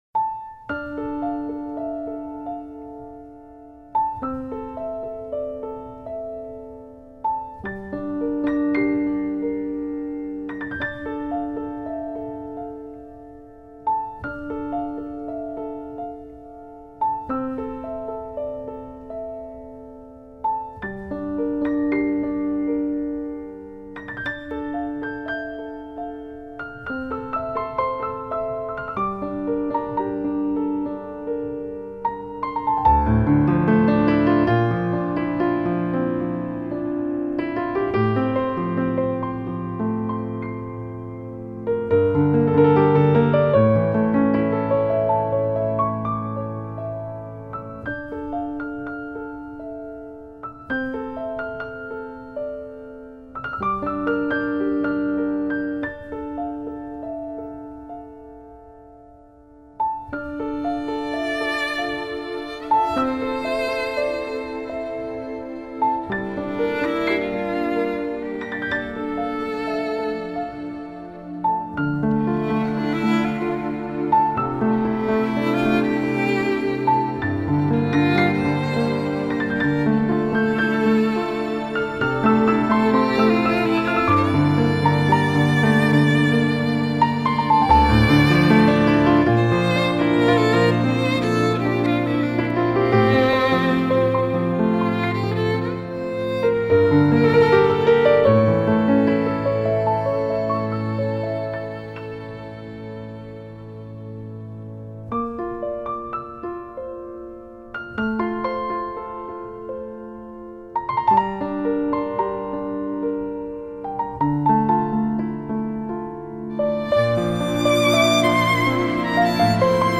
ارامش بخش